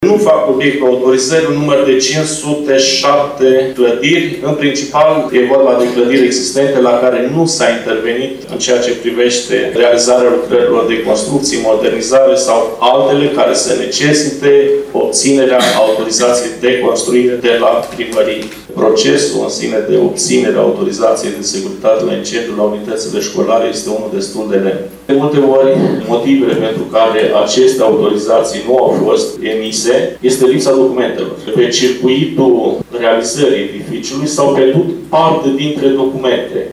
Referitor la autorizarea de securitate la incendiu a școlilor, șeful Inspectoratului pentru Situații de Urgență Suceava colonel COSTICĂ GHIAȚĂ a menționat că 278 de clădiri trebuie să intre în legalitate.